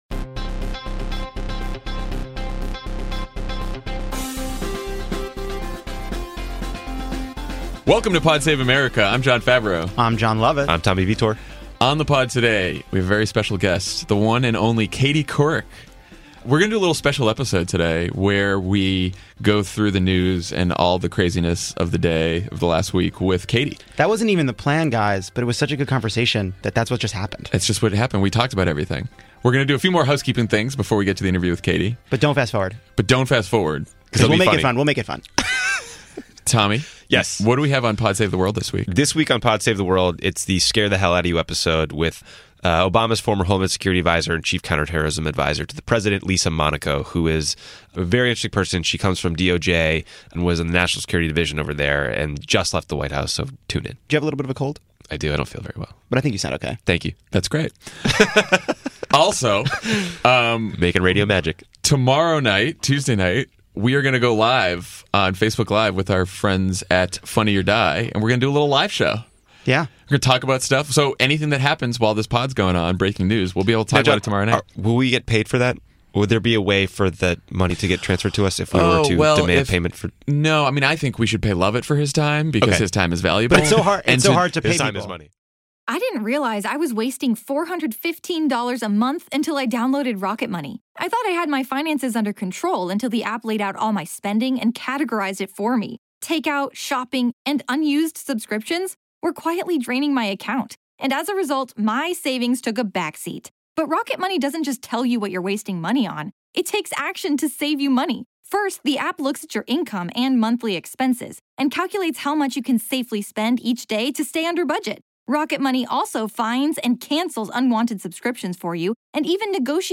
Katie Couric joins for a freewheeling conversation about Trump's attacks on the media, persuading Trump voters and the future of the Democratic Party.